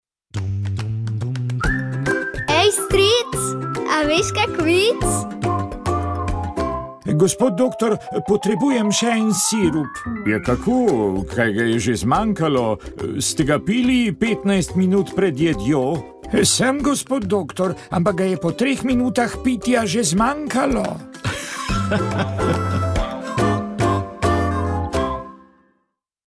Duhovni nagovor
Nagovor in razmišljanje ob evangeliju letošnje zahvalne nedelje je pripravil celjski škof msgr. dr. Maksimilijan Matjaž.